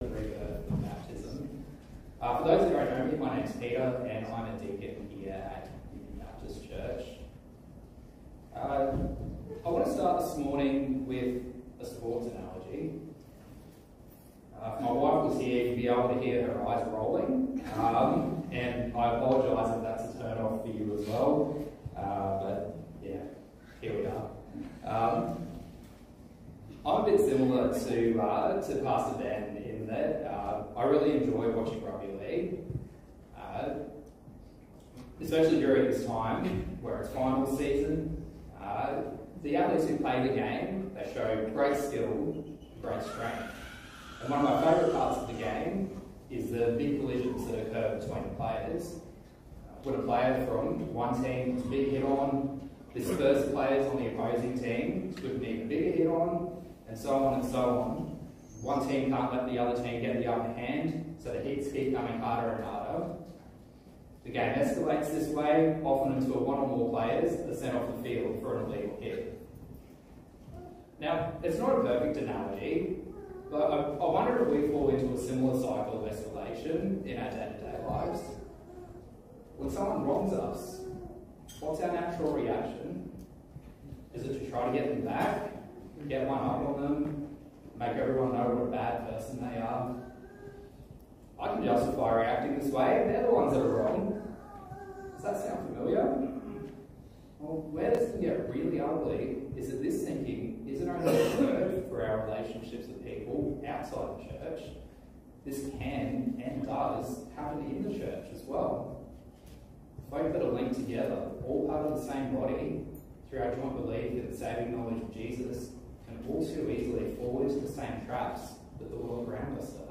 Service Type: Sunday Morning
Gympie-Baptist-Live-Stream-6th-October-2024.mp3